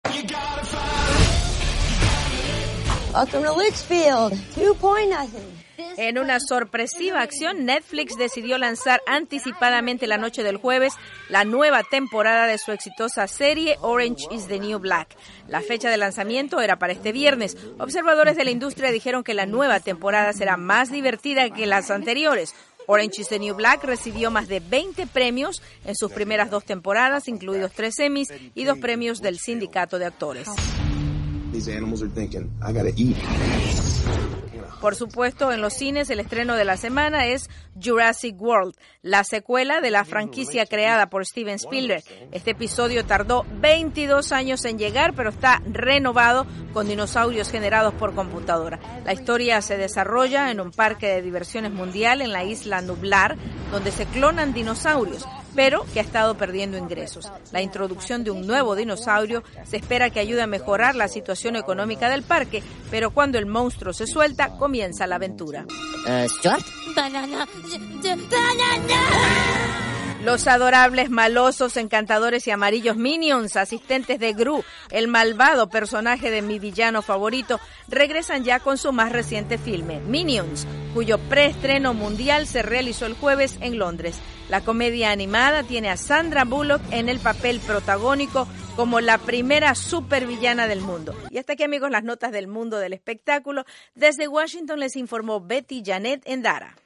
VOA: Noticias del Entretenimiento